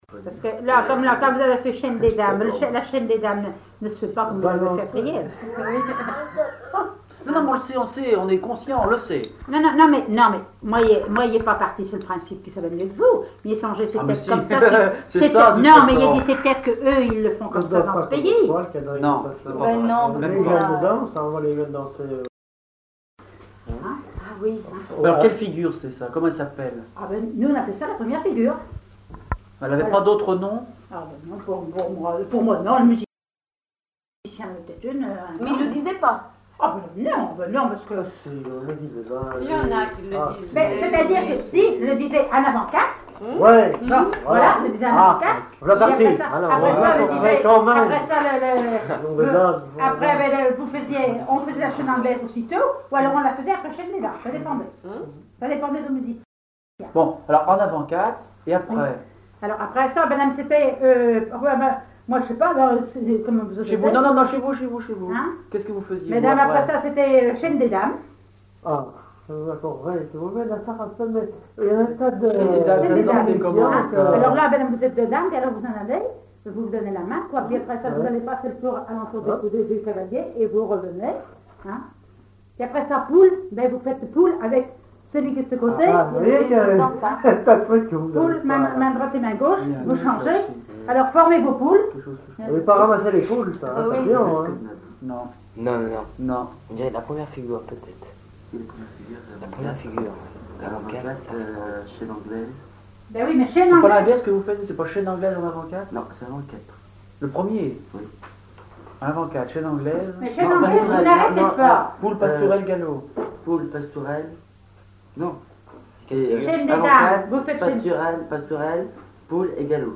Enquête Les Bottarouzous, de Triaize (Association culturelle)
Catégorie Témoignage